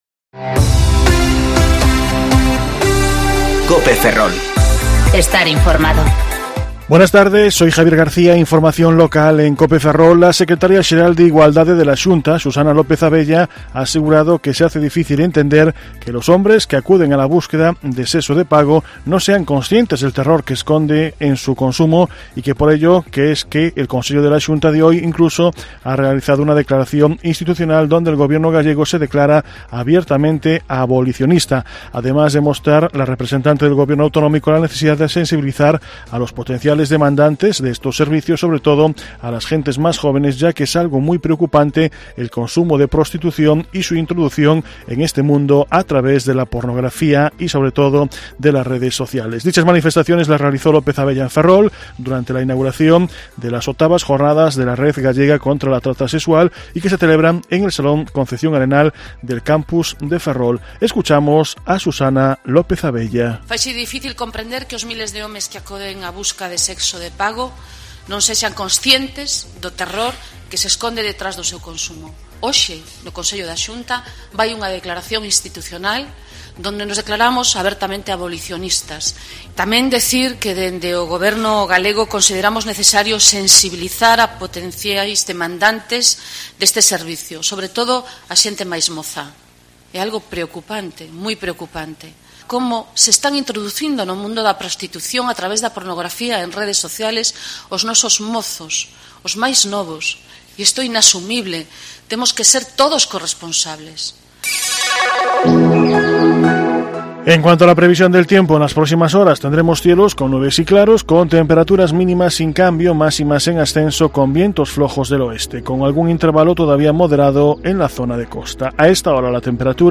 Informativo Mediodía Cope Ferrol 17/10/2019 (De 14.20 a 14.30 horas)